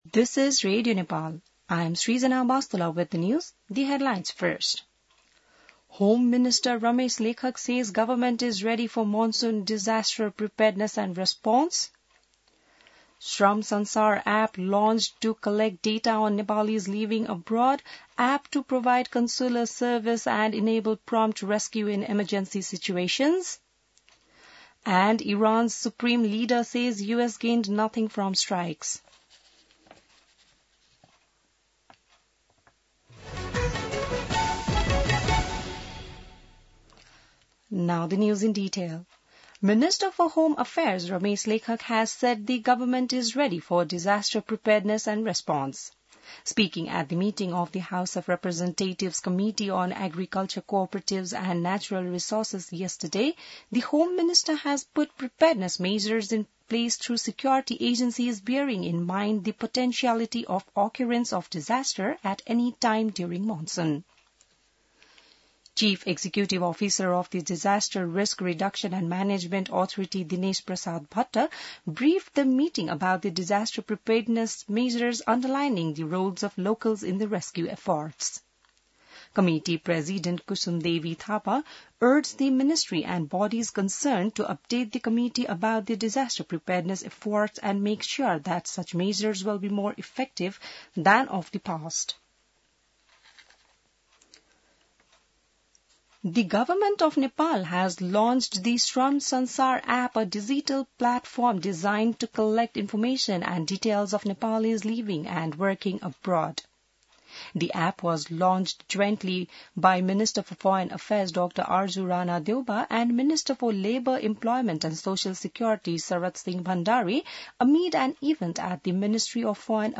बिहान ८ बजेको अङ्ग्रेजी समाचार : १३ असार , २०८२